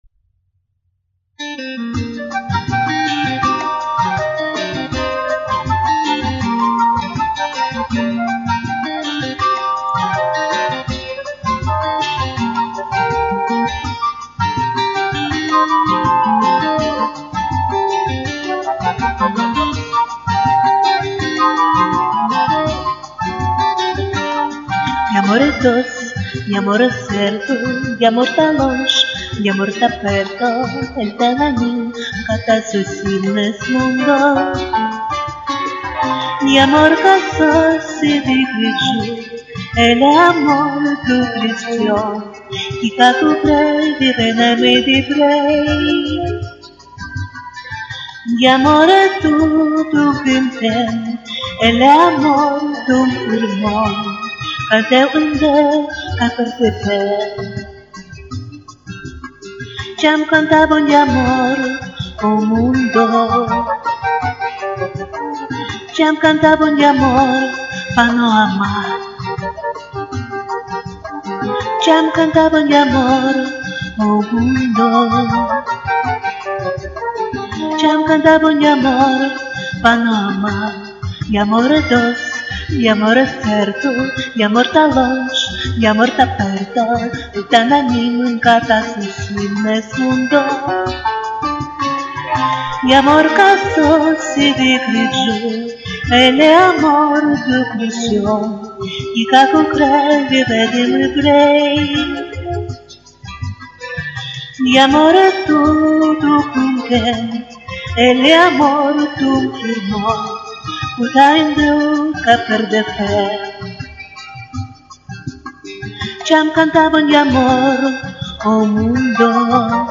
На моем компьютере ваша запись идет с сильными искажениями.